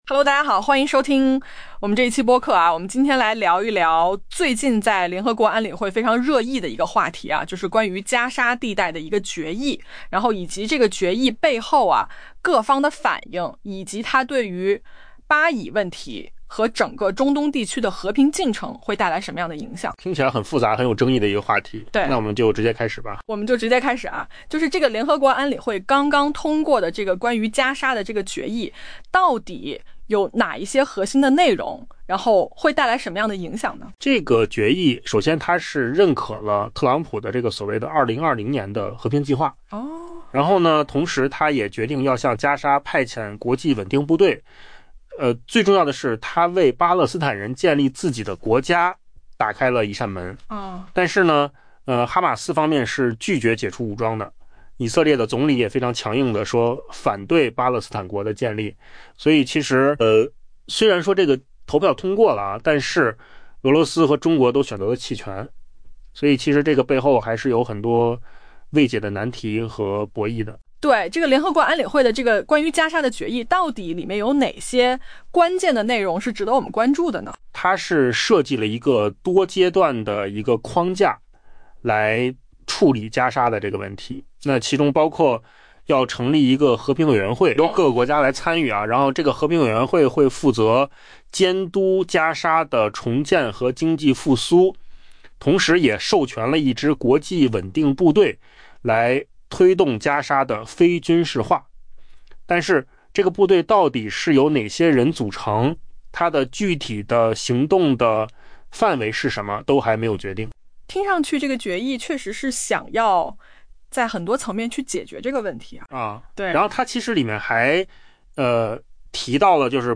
AI播客：换个方式听新闻 下载mp3 音频由扣子空间生成 联合国安理会周一投票通过一项由美国起草的决议，认可美国总统特朗普结束加沙战争的计划，并授权向这一巴勒斯坦飞地派遣国际稳定部队。